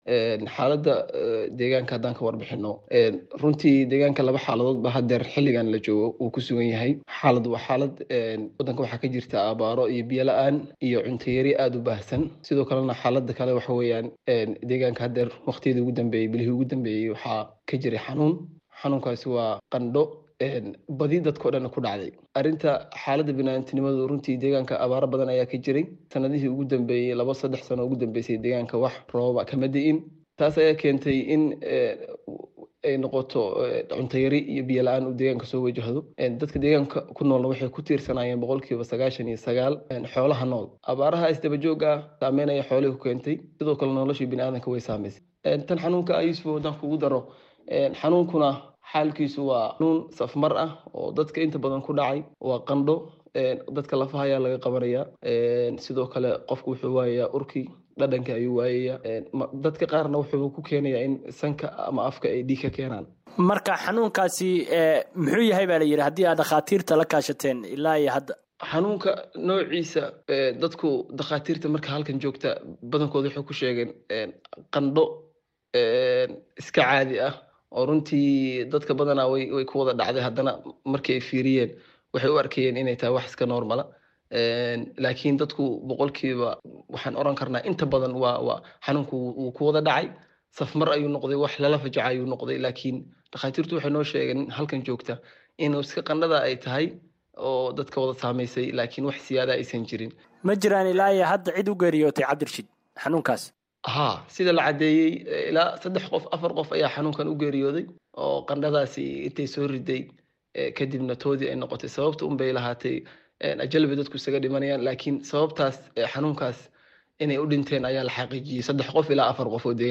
Guddoomiyaha degmada Rako Raaxo ee gobolka Karkaar, Cabdirashiid Cumar Siciid ayaa VOA u sheegay in xanuunka uu wato qufac iyo qandho daran, taas oo sababtay in, inta ay xaqiijiyeen, afar qof oo labo ka mid ah ay yihiin dumar ay u dhinteen.